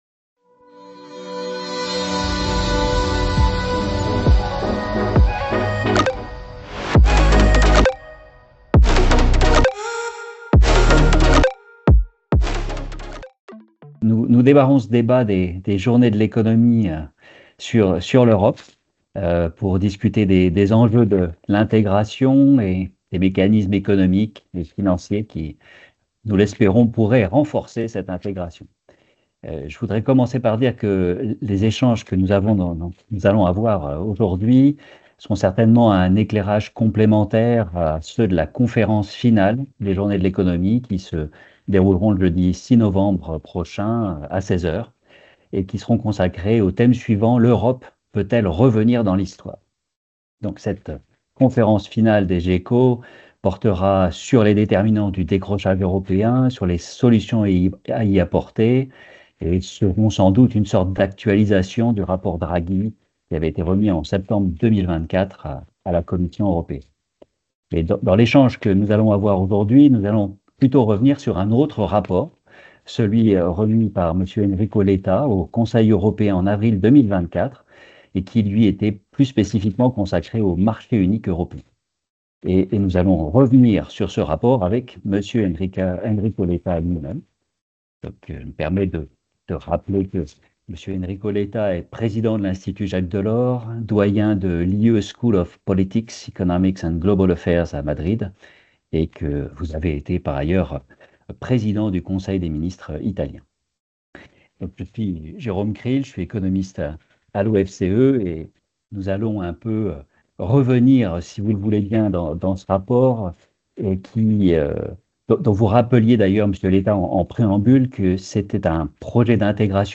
Conférence ou documents liés à une conférence